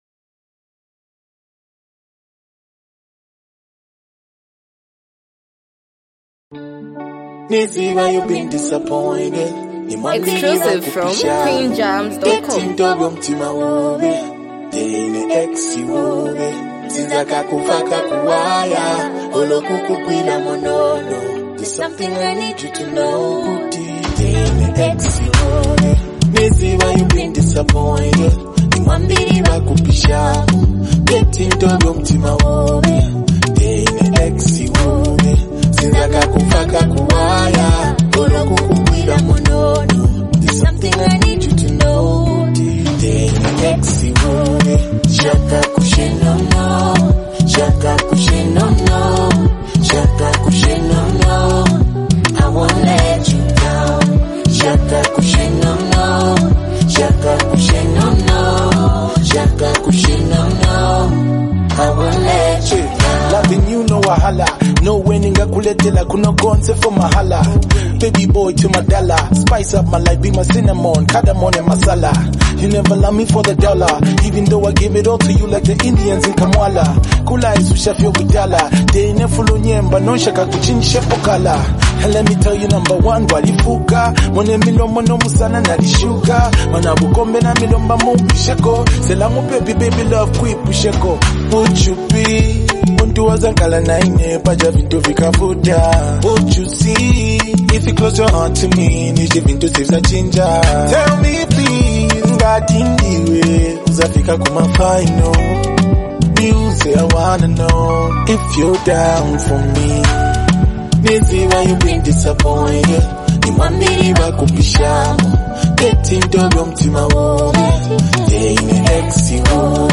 reflective and emotionally charged song
smooth, heartfelt delivery